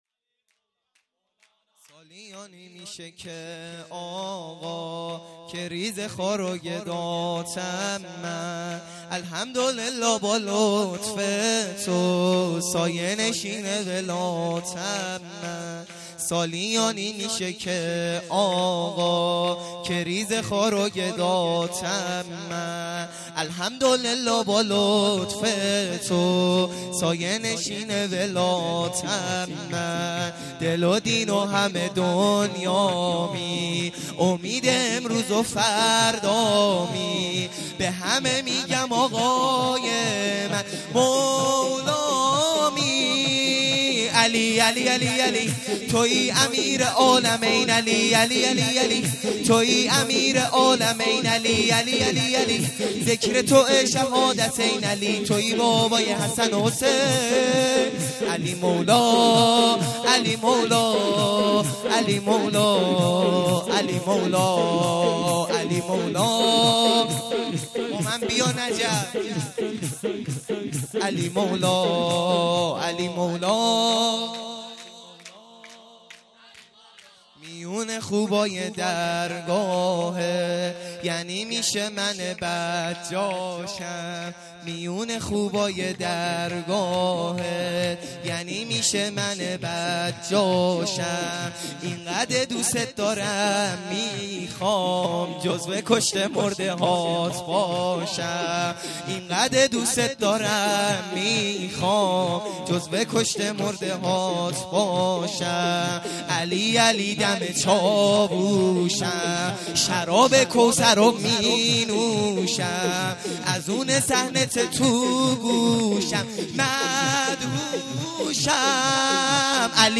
سرود | سالیانی که میشه اقا